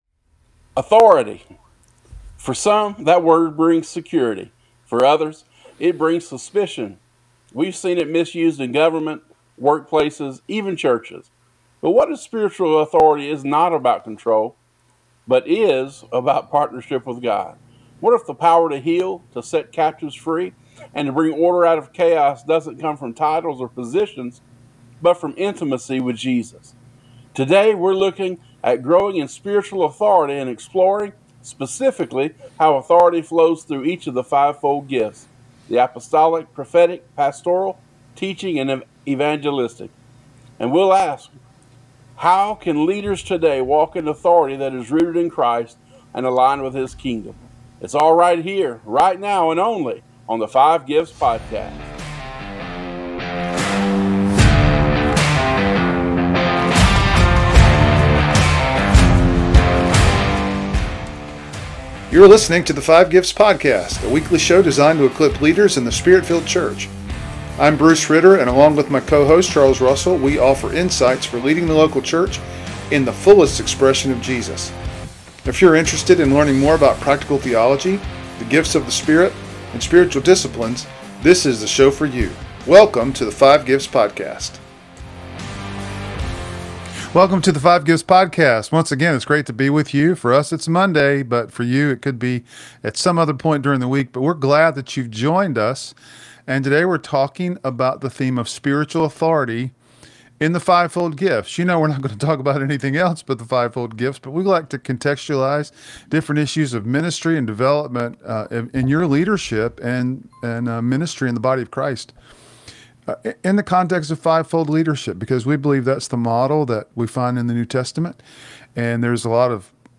Whether you’re serving in the pulpit, the marketplace, or your community, this conversation will strengthen your confidence in Christ’s authority at work in you.